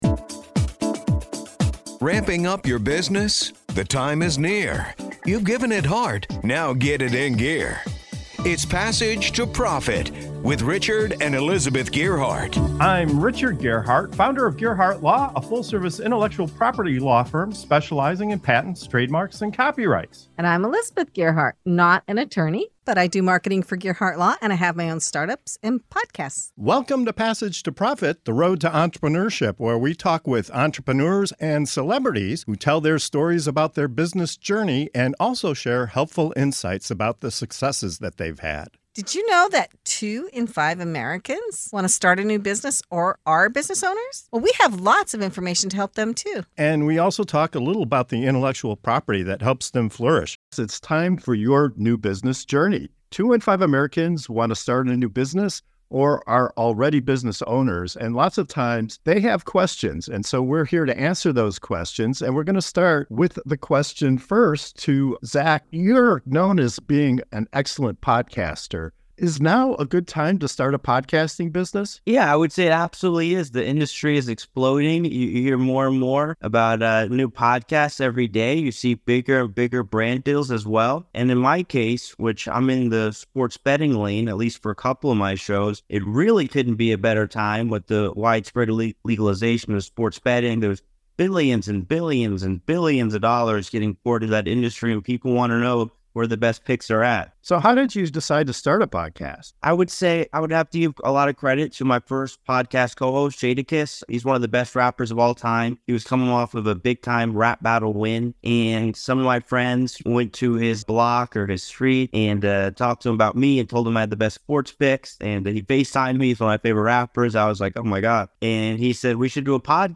Ready to take the leap into business ownership? In this segment of "Your New Business Journey" on Passage to Profit Show, our panel dives into the booming opportunities in podcasting, solar energy, and intellectual property.